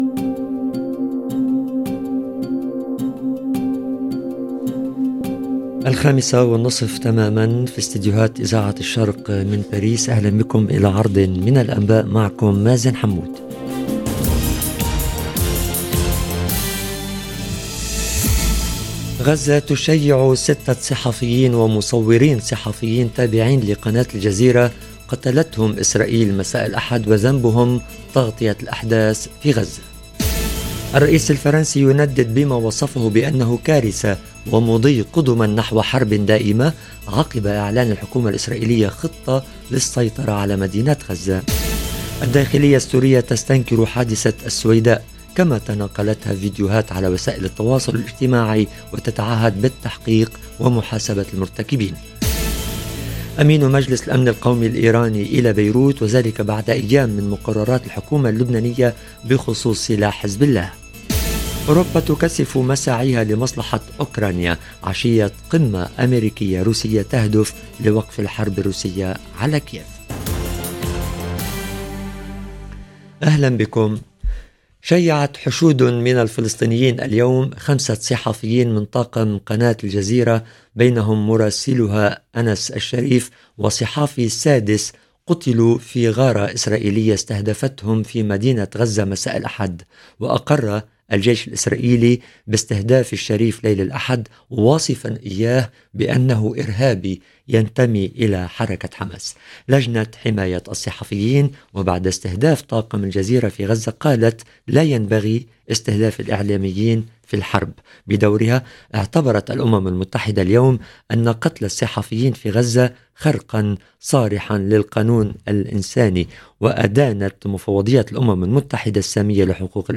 نشرة أخبار المساء: الرئيس الفرنسي يندد بما وصفه بانه كارثة، ومقتل ست صحفيين لقناة الجزيرة في غزة - Radio ORIENT، إذاعة الشرق من باريس